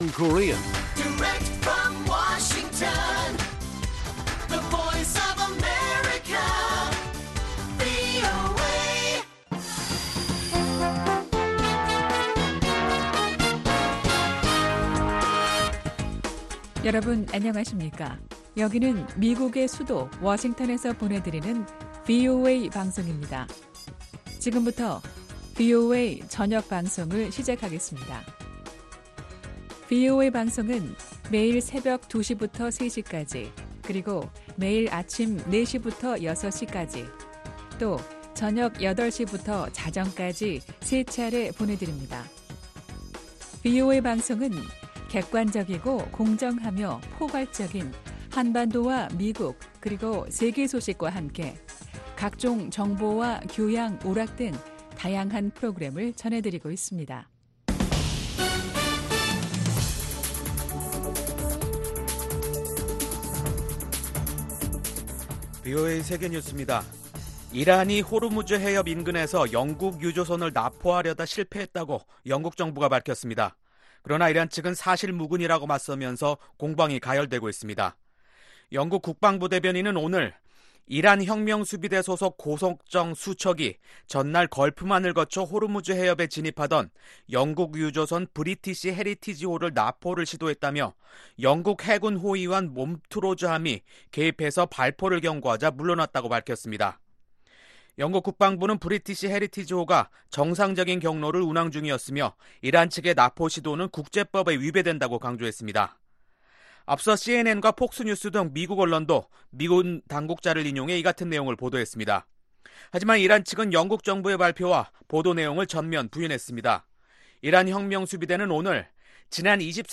VOA 한국어 간판 뉴스 프로그램 '뉴스 투데이', 2019년 7월 11일 1부 방송입니다. 주한미군 사령부는 북한의 대륙간 탄도 미사일 ICBM급 화성-15호가 미국 본토 전역을 타격할 수 있다고 평가했습니다. 미국의 한반도 전문가들은 북핵 동결에 대한 국무부의 발표에 대해, 단계적 비핵화 의사를 내비치면서, 완전한 비핵화 목표는 변함이 없다는 점을 재확인한 것으로 평가했습니다.